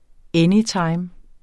anytime adverbium Udtale [ ˈεniˌtɑjm ]